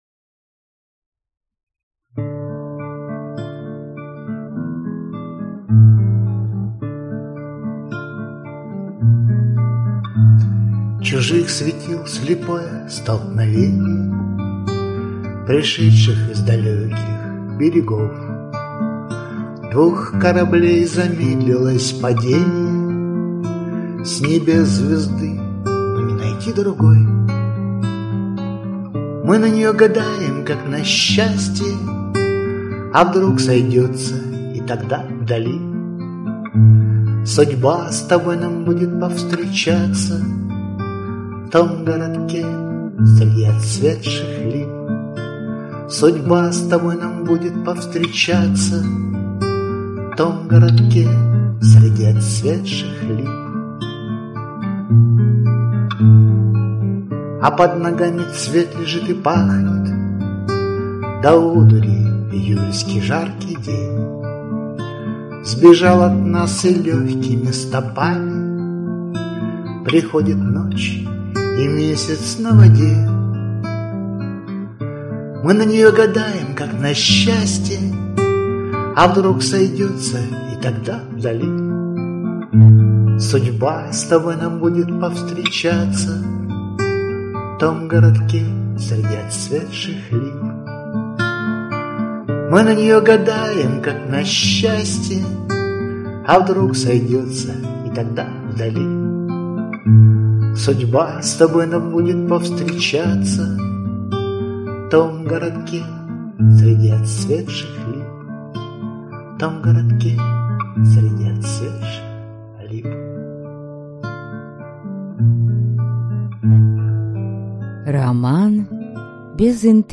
Аудиокнига Роман без интриги | Библиотека аудиокниг